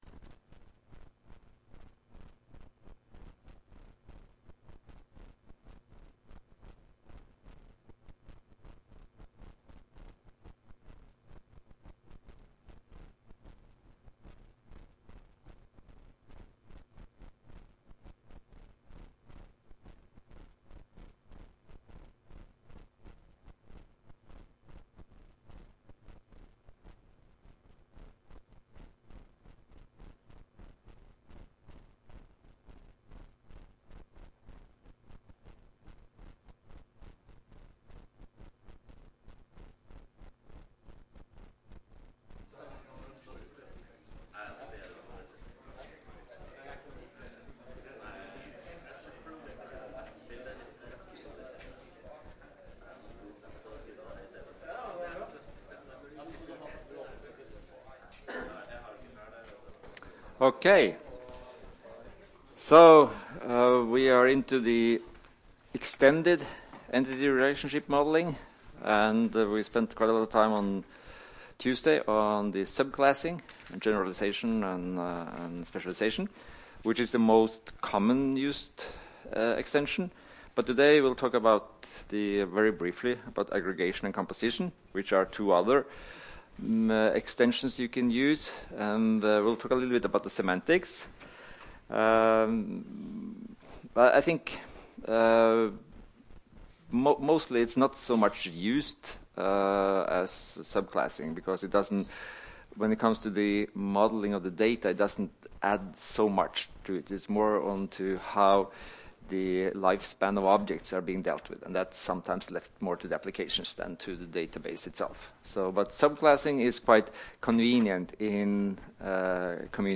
Rom: Smaragd 1 (S206)